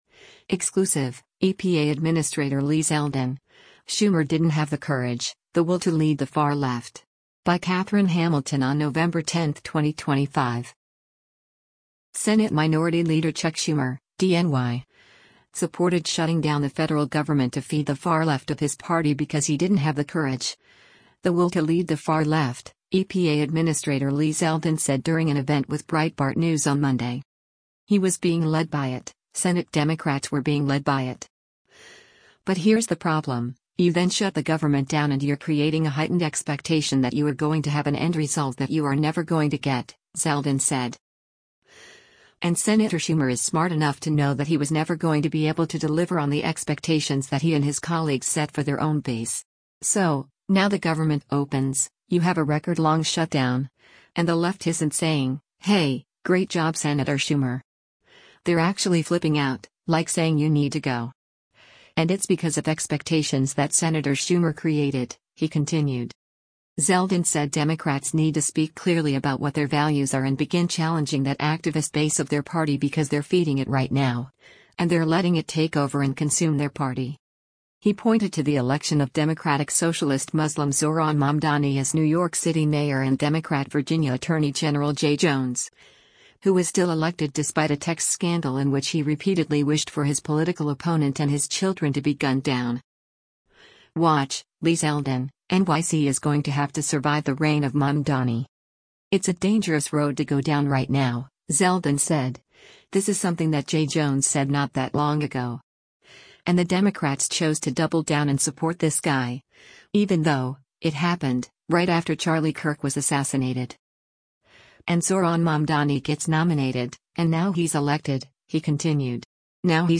Exclusive — EPA Administrator Lee Zeldin: Schumer ‘Didn’t Have the Courage, the Will to Lead the Far Left’
Senate Minority Leader Chuck Schumer (D-NY) supported shutting down the federal government “to feed the far left of his party because he didn’t have the courage, the will to lead the far left,” EPA Administrator Lee Zeldin said during an event with Breitbart News on Monday.